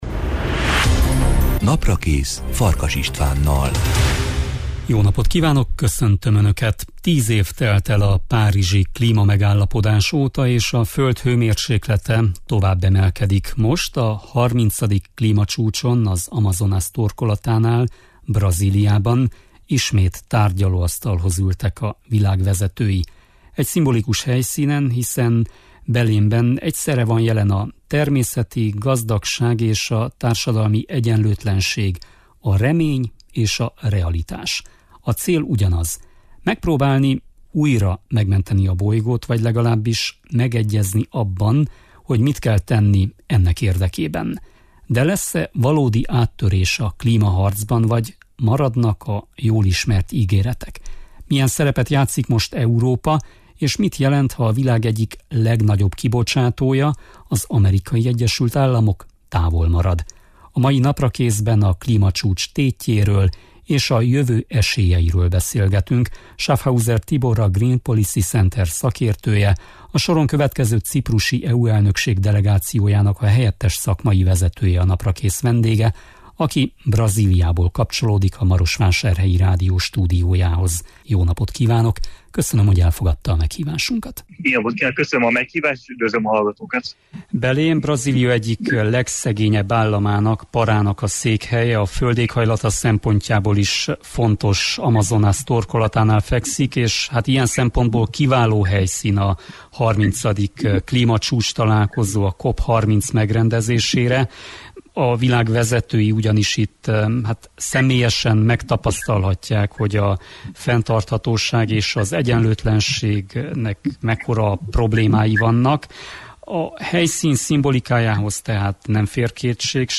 aki Brazíliából kapcsolódik a Marosvásárhelyi Rádió stúdiójához.